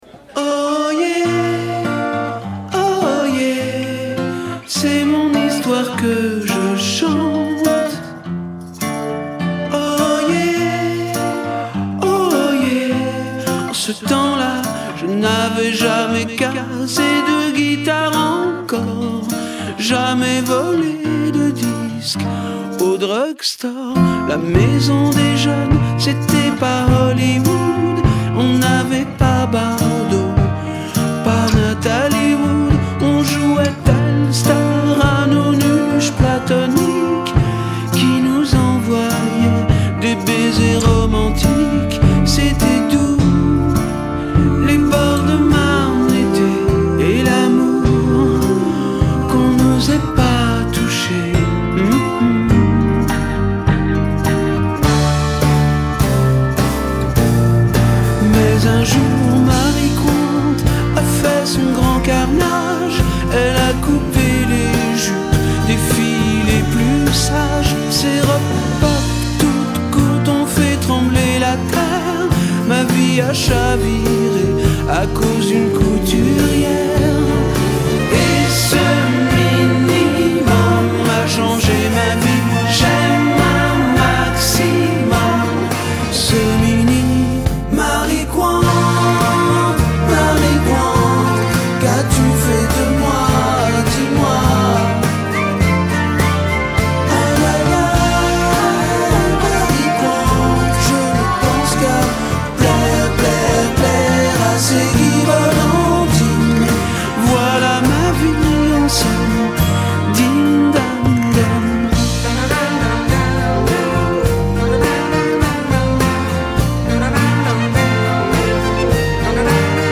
mais plutôt folk avec une gaieté sincère